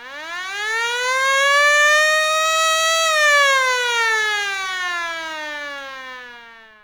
siren.wav